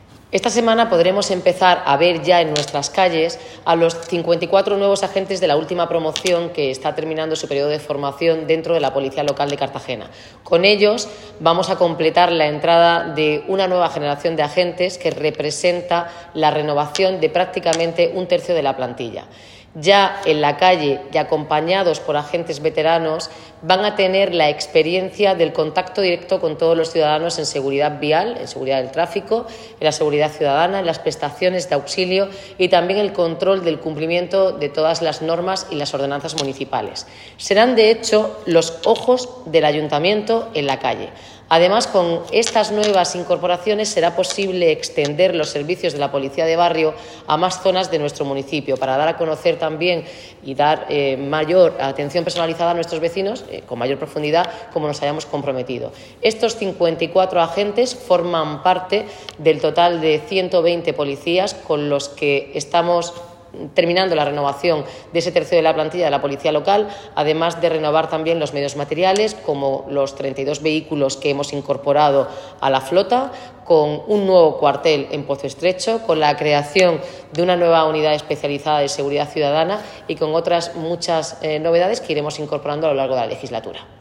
Enlace a Declaraciones de la alcaldesa Noelia Arroyo.